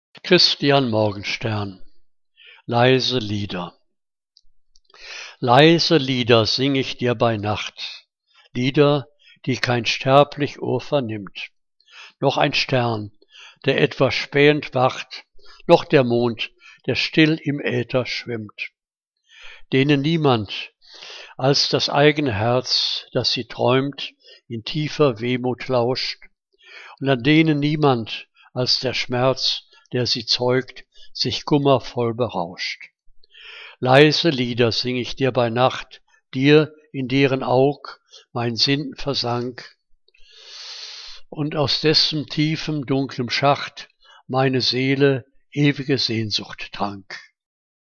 Liebeslyrik deutscher Dichter und Dichterinnen - gesprochen (Christian Morgenstern)